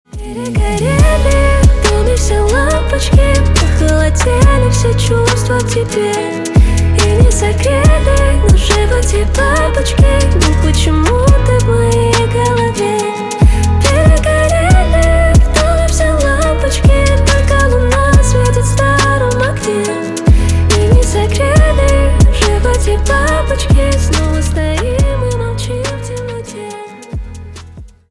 на русском про любовь на парня грустные